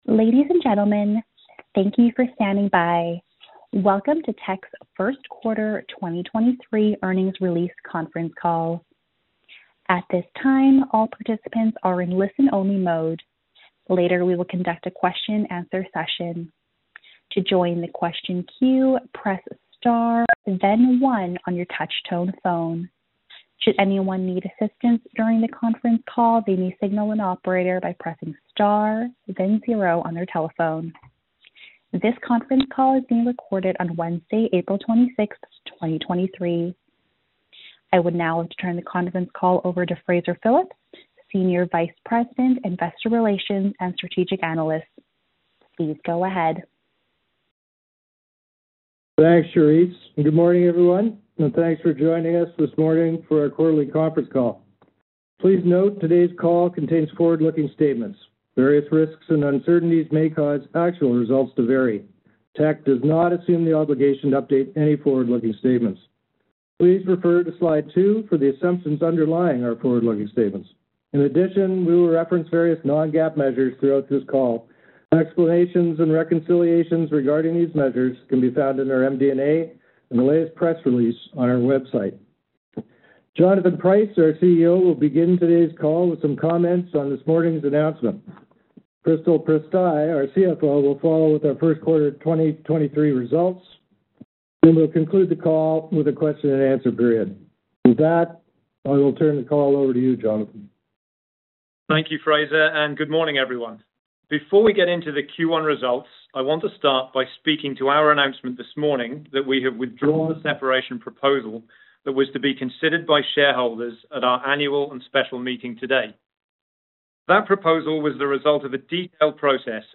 Q1 2023 Conference Call